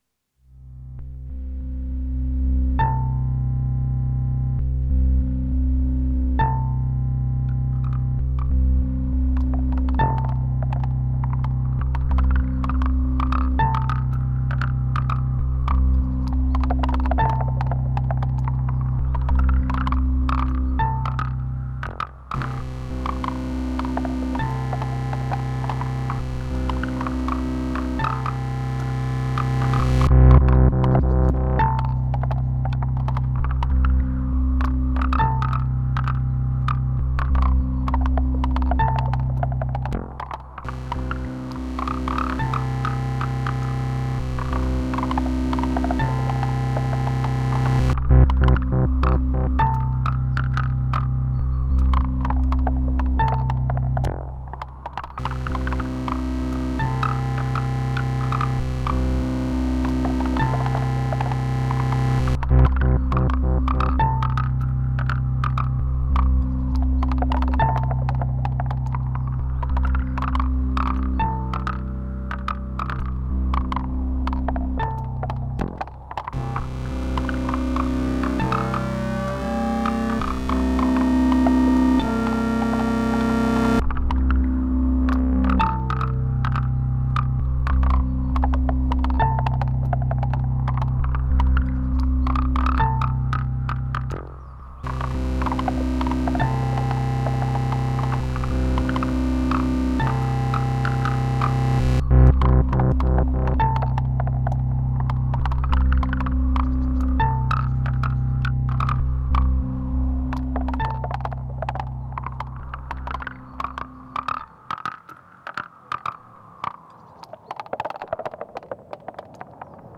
если закрыть глаза - чем не секвенированный модуляр
на улице капель взял рекордер, положил под капли пластиковую трубу, записал и быстренько наживо прям с рекордера засунул в пару нейтронов а скоро мои пьезики приедут Вложения neutron drop.mp3 neutron drop.mp3 5 MB · Просмотры: 997